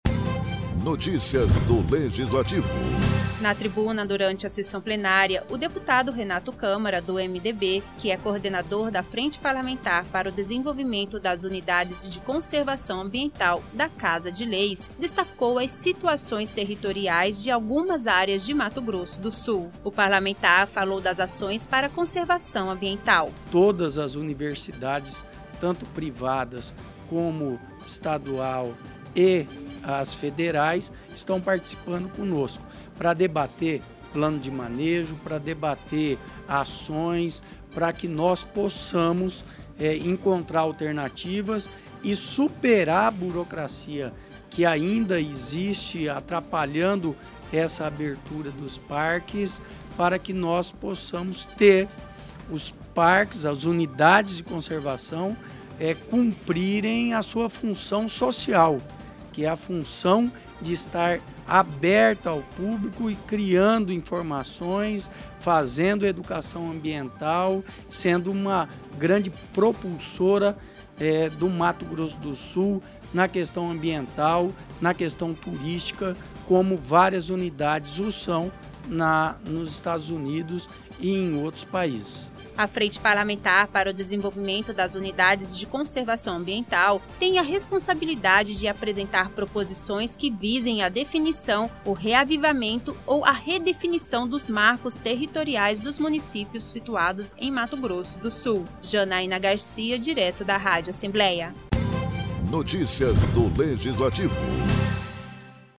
O deputado estadual Renato Câmara, do MDB falou na tribuna da Casa de Leis sobre os dados da reunião da Comissão Especial de Limites e Divisas Territoriais.